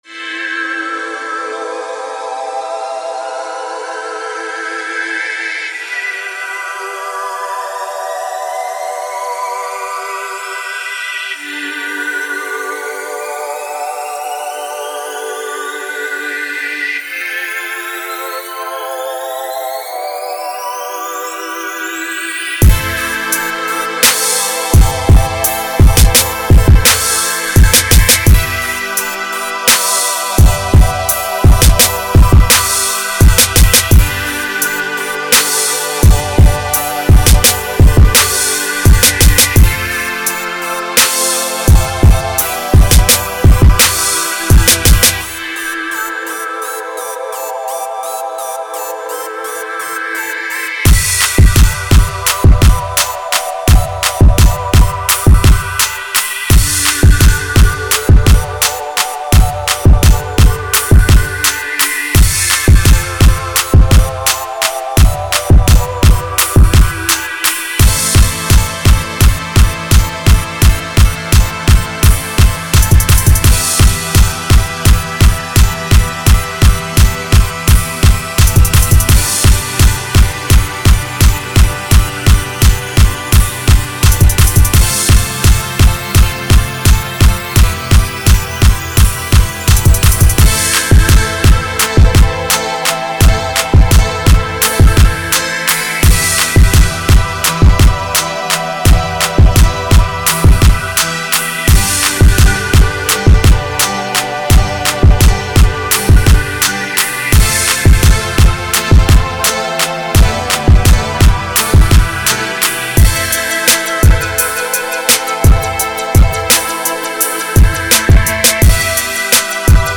Uptempo RnB Track 85 BPM